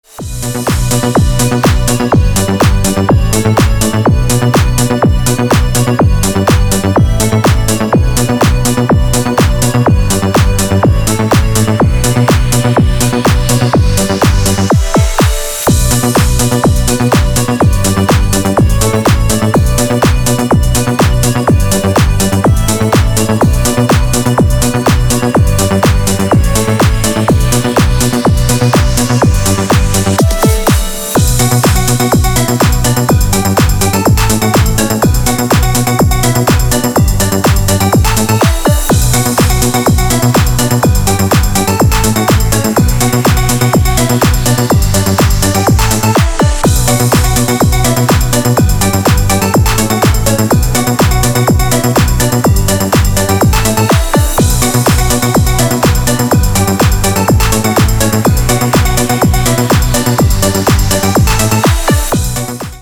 Музыка в стиле 90-х на вызов